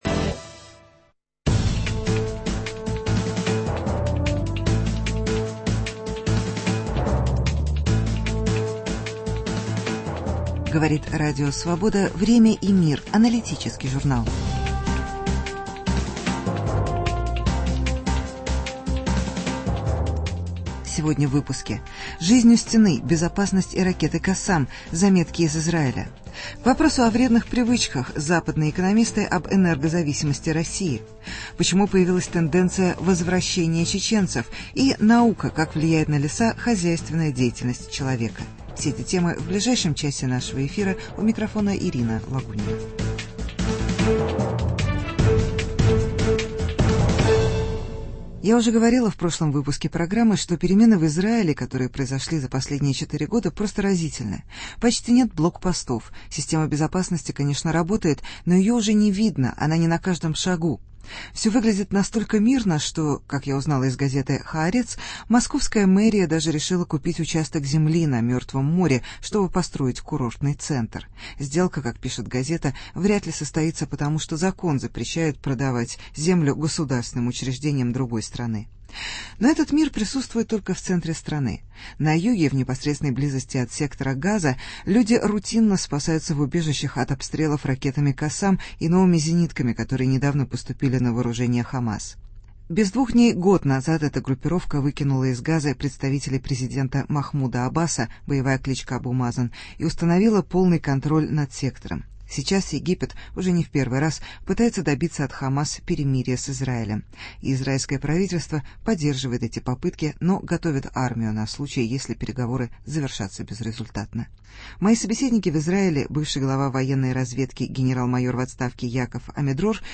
Израильские репортажи, ч. 2. Интервью